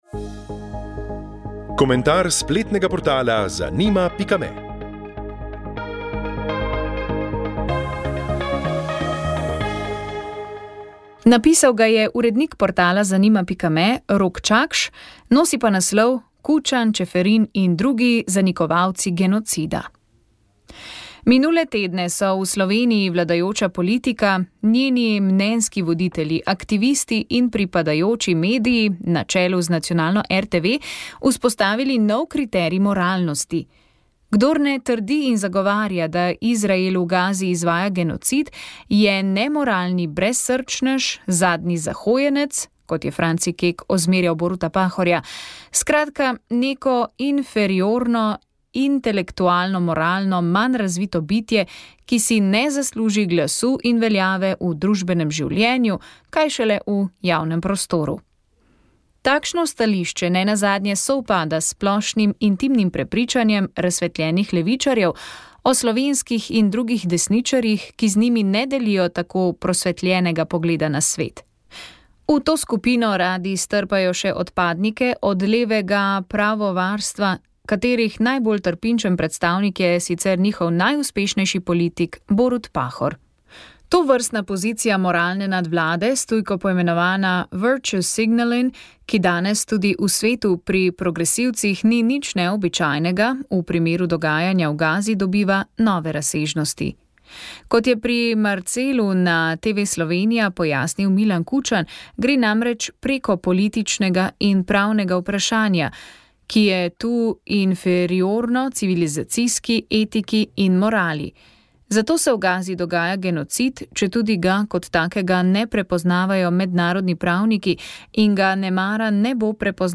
Informativni prispevki